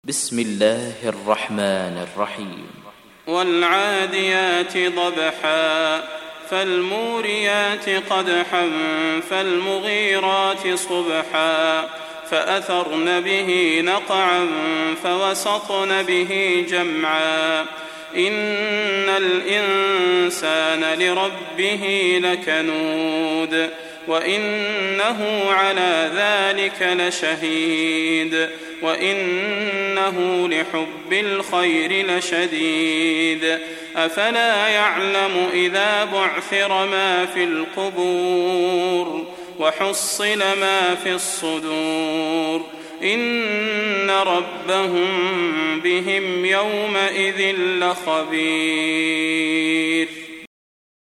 دانلود سوره العاديات mp3 صلاح البدير (روایت حفص)